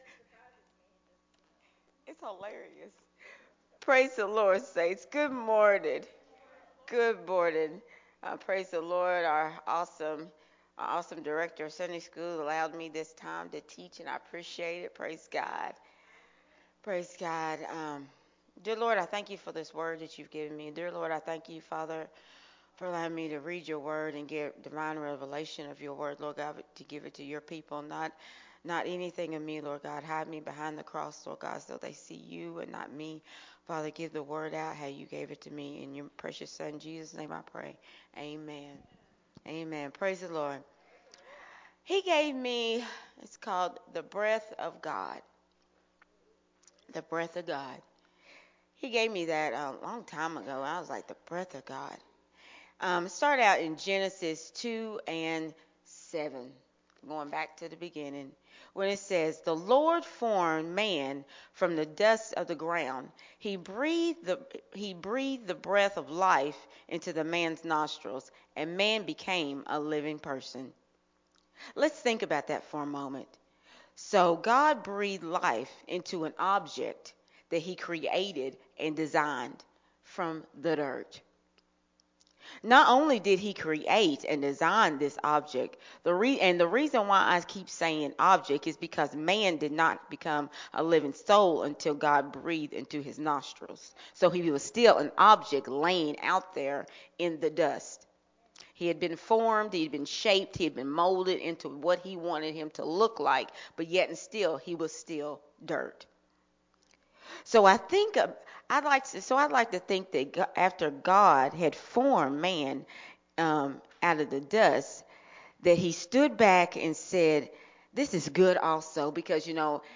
recorded at Unity Worship Center on October 9th, 2022.